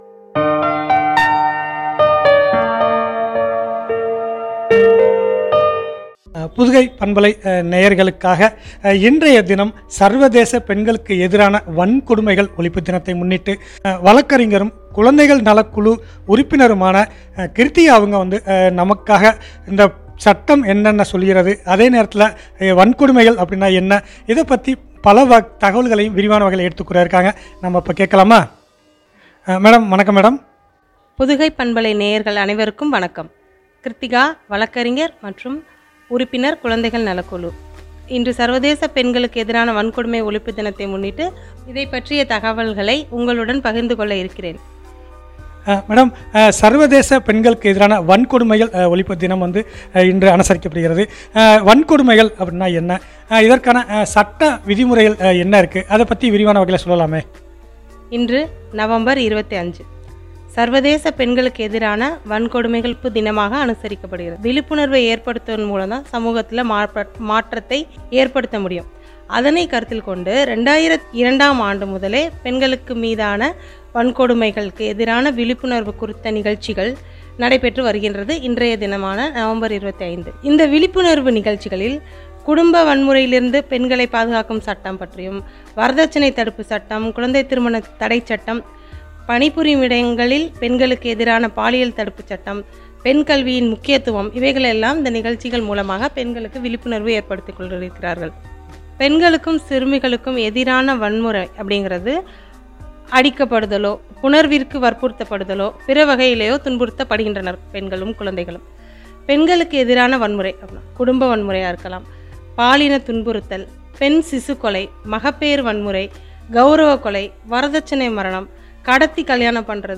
” மாண்புமிகு மகளிர் ” குறித்து வழங்க உரையாடல்.